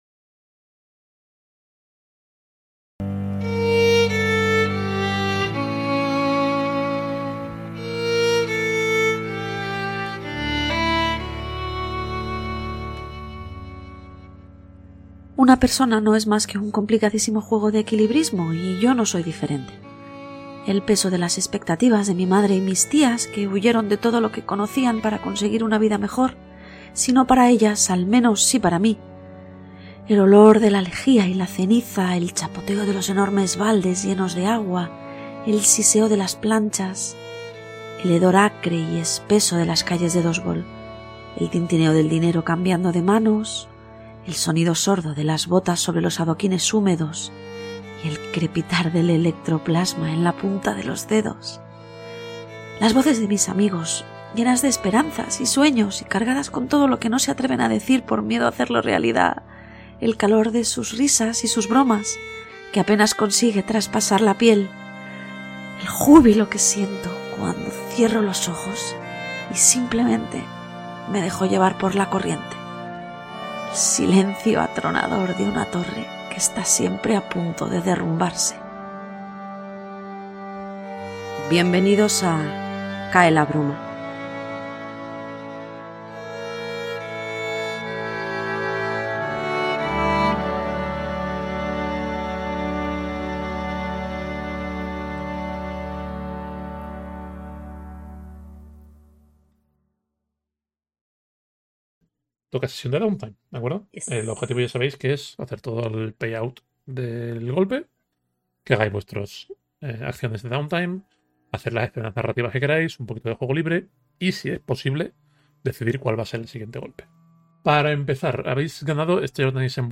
Cae la Bruma es una campaña de rol que se publica en formato podcast en Pasillo 8 y en formato stream en Éxito Crítico. El juego al que jugamos es Blades in the Dark, diseñado por John Harper y publicado en España por Nosolorol.